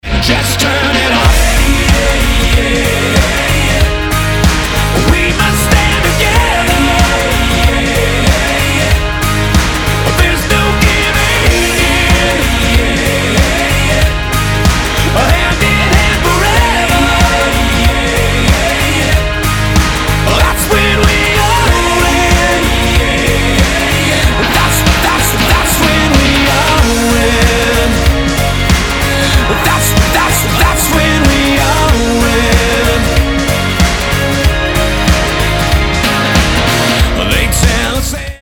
Kanadská rocková skupina
je príjemná mainstreamová skladba.